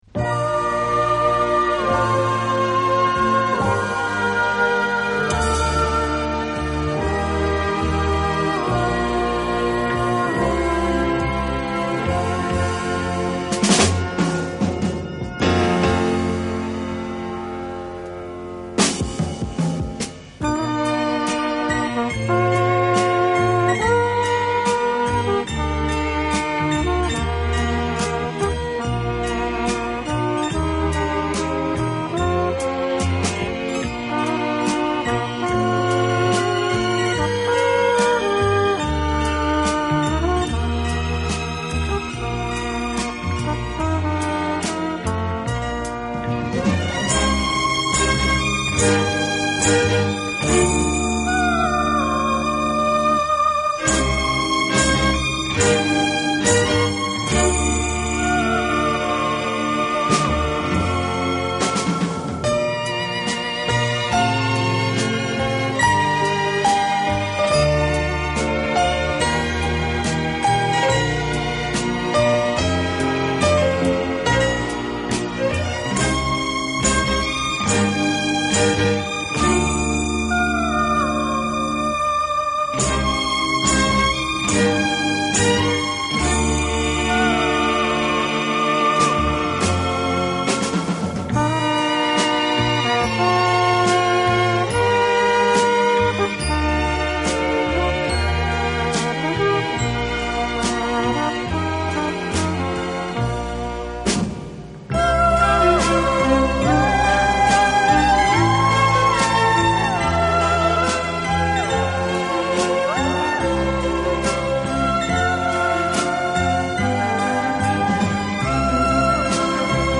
Genero/Genre: Instrumental/Easy Listening